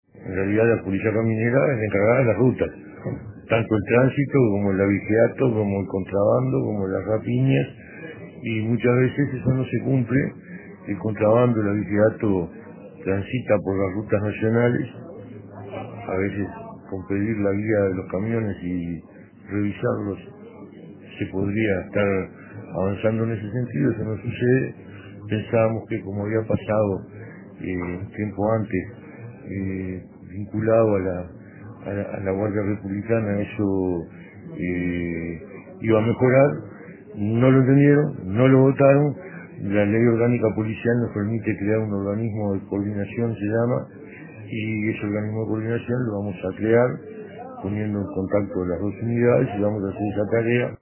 Escuche al ministro